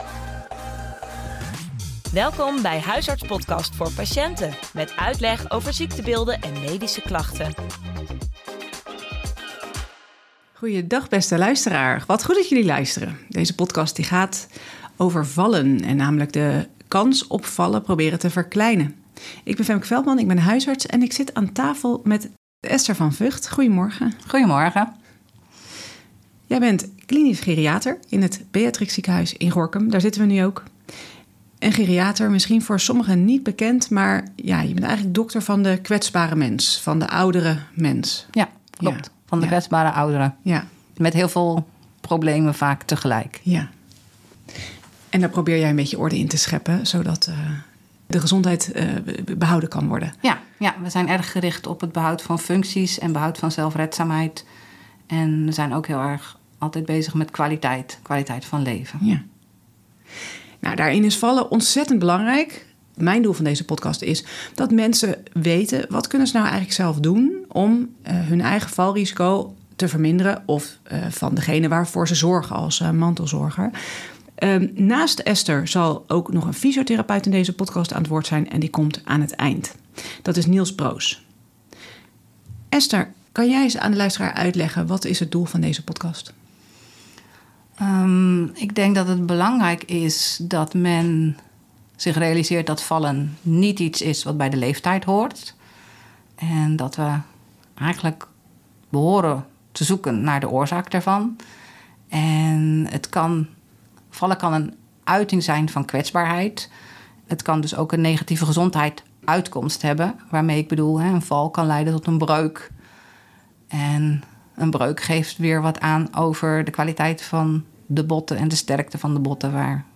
Het doel van deze podcast is om uit te leggen wat iemand zelf of met behulp van andere kan doen om de kans op vallen en botbreuken te verkleinen. In deze podcast komen twee sprekers aan het woord